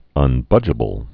(ŭn-bŭjə-bəl)